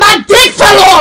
My Dk Fell Off (extra Loud Version)